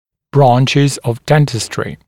[‘brɑːnʧɪz əv ‘dentɪstrɪ][‘бра:нчиз ов ‘дэнтистри]области стоматологии